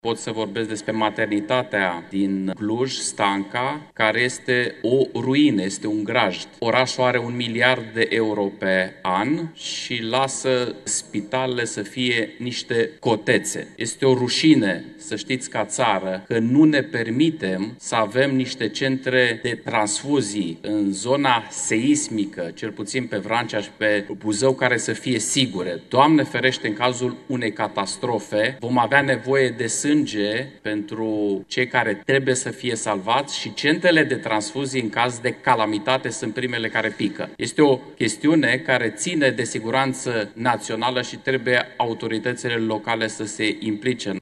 Emanuel Ungureanu, deputat USR: „Este o rușine, să știți, ca țară, că nu ne permitem să avem niște centre de transfuzii în zona seismică”